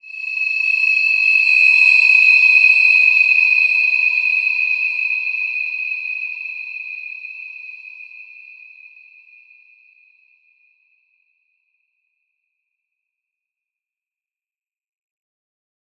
Wide-Dimension-E6-f.wav